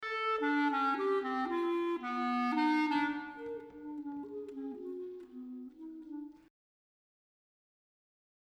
Drake Mabry, another American composer, adopts the use of quarter-tones for several musical purposes in his Street Cries (1983) for solo clarinet.
These repetitions are not only a quarter-tone removed, but also display a strikingly different timbre. Measure 7 is not only much softer than measure 6, but has a thinner sound. Measure 37 sounds thicker and darker than measure 36.